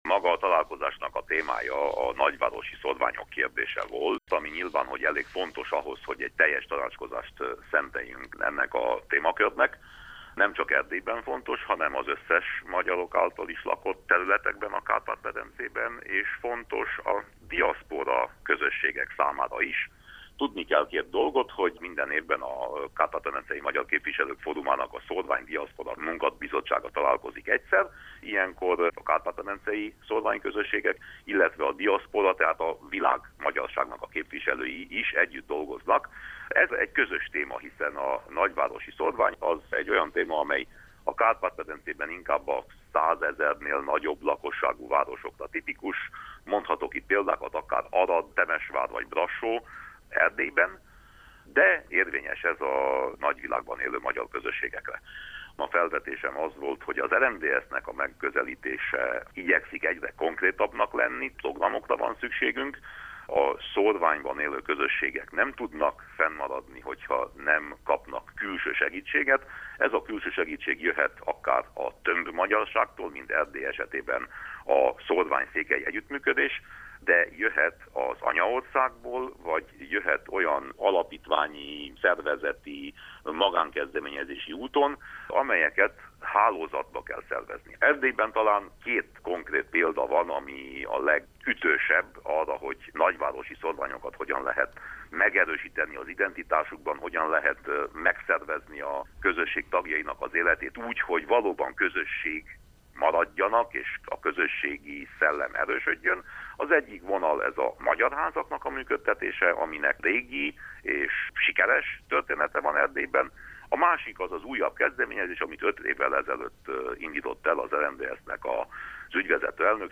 A nagyvárosi szórvány volt a fő témája a Kárpát-medencei Magyar Képviselők Fóruma szórvány-diaszpóra munkacsoportja éves ülésének Budapesten. A tanácskozáson részt vett Winkler Gyula, az RMDSZ európai parlamenti képviselője is, akit a tanácskozásról, valamint az általa támogatott szórvány projektről faggatunk.